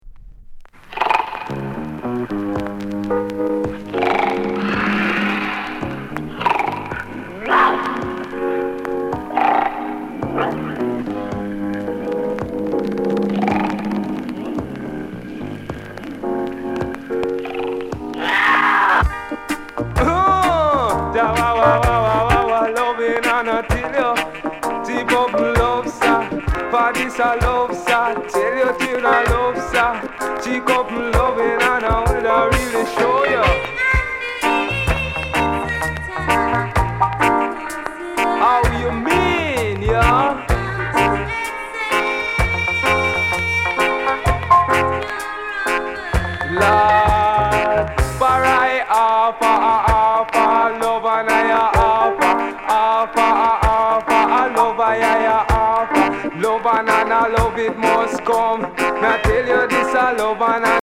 Sound Condition VG(OK)
LOVERS ROCK